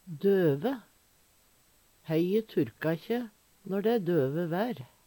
Høyr på uttala Ordklasse: Adjektiv Kategori: Vêr og føre Attende til søk